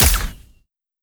Hitech Shot B.wav